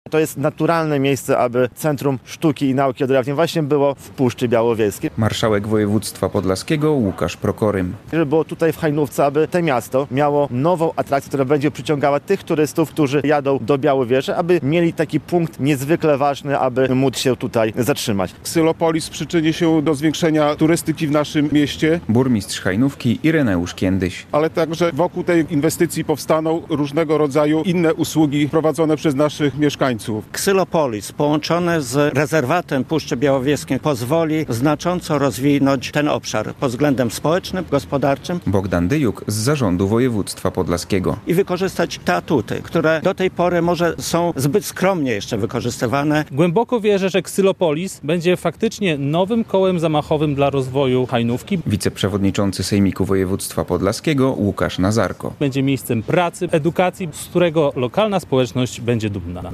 Xylopolis w Hajnówce - relacja